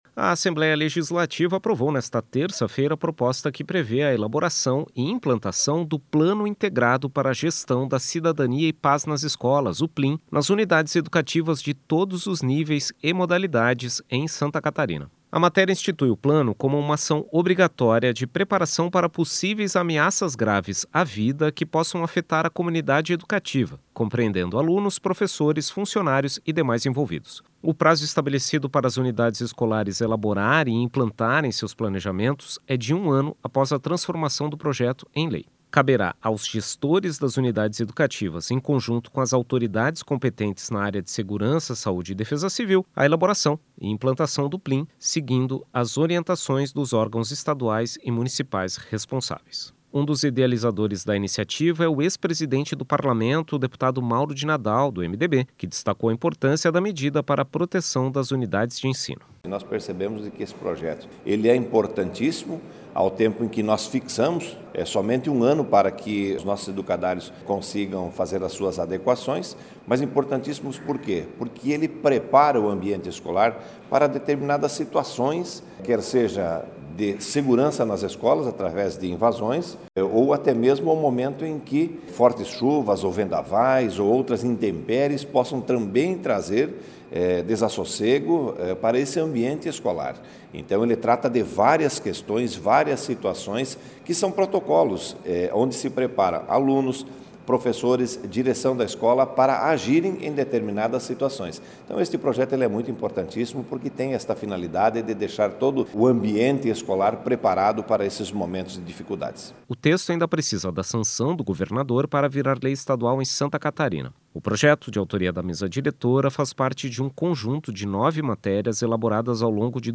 Entrevista com: